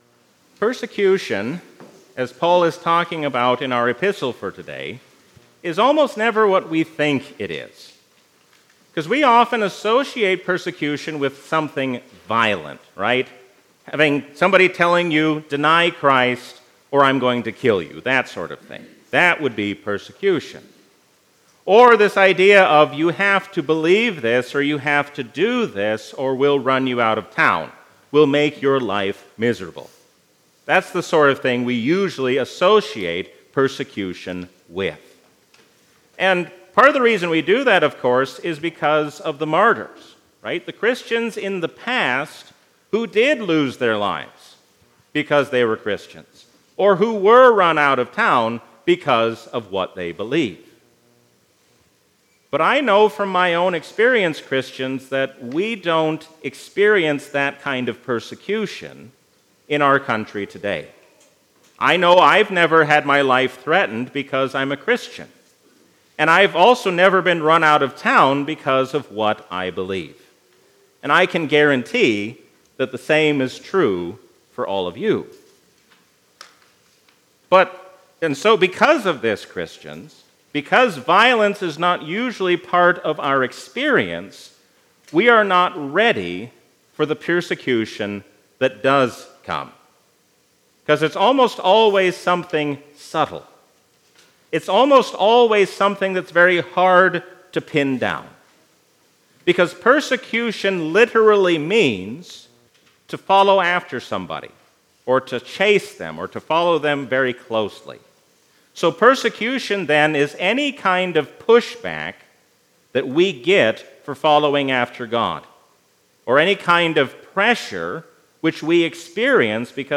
A sermon from the season "Trinity 2022." Stand firm against the hostile world, because Jesus reigns as the King of Kings and Lord of Lords forever.